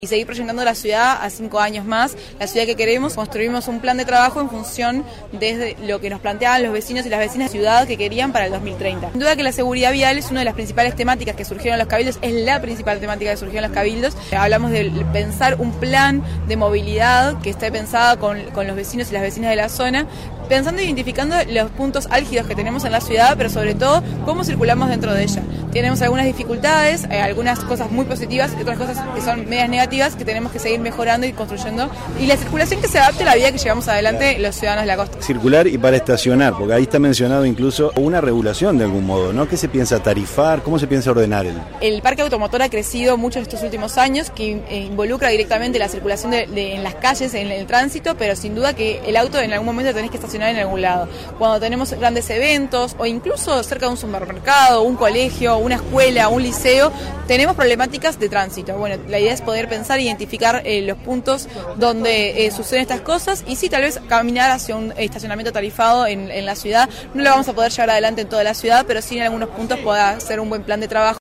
La alcaldesa Julia Matilla indicó que el Plan de Desarrollo Local va más allá del diseño urbano definido por el “Costa Plan” y se busca dar una visión más integral al desarrollo de la ciudad que más ha crecido en todo el país.